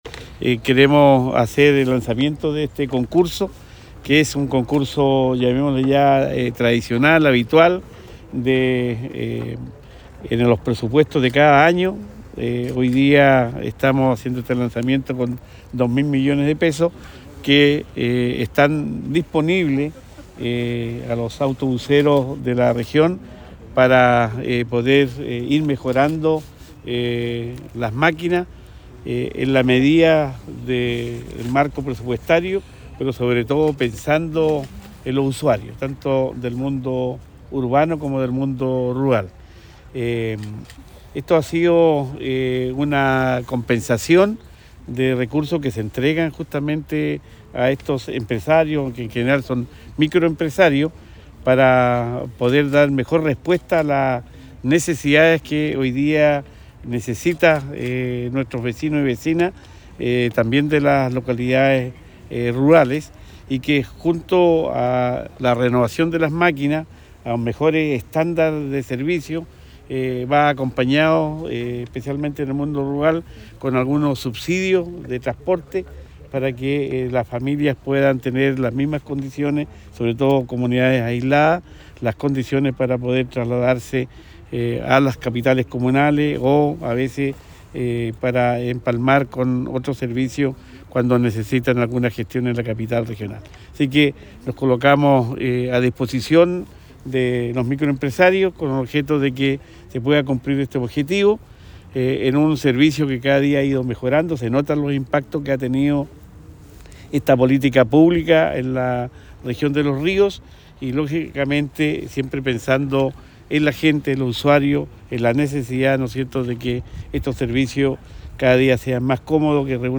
Cuña_Luis-Cuvertino_Renueva-Tu-Micro-2022.mp3